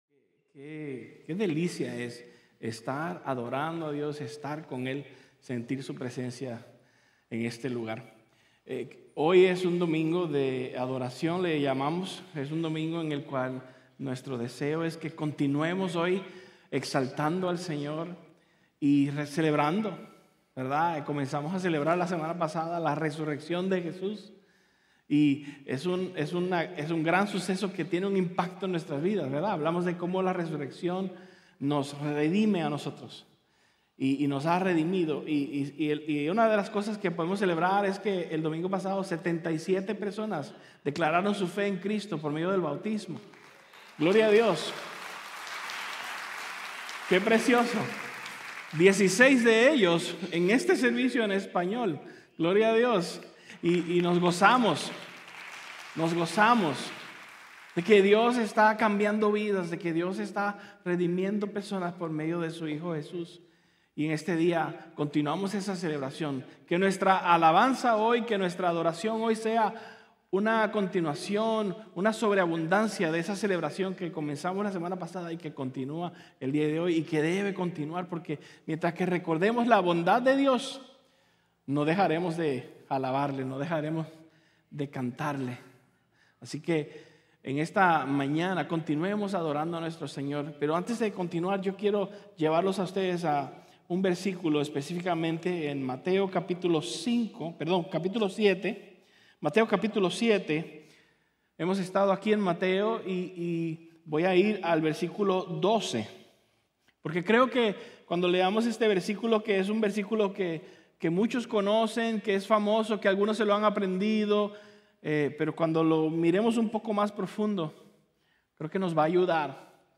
Sermón del Monte